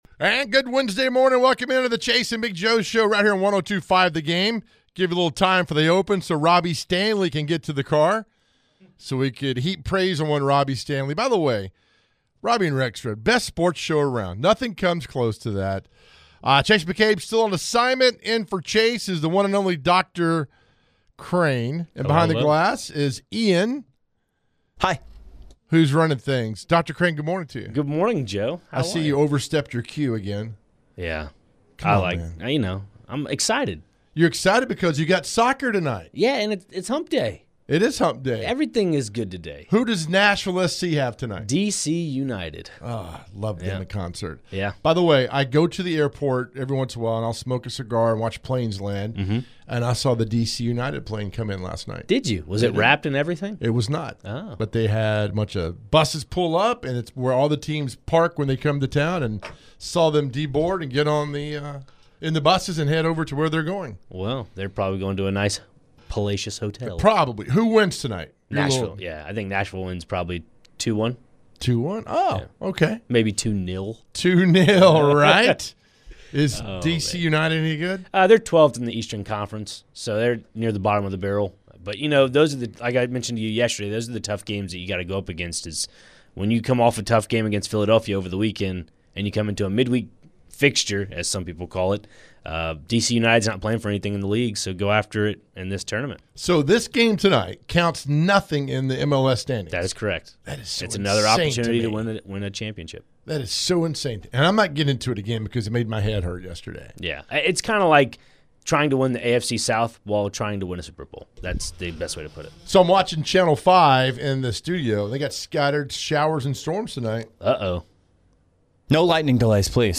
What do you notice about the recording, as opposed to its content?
They begin the show with our best number 45s and the Question of the Day. We head to your phones.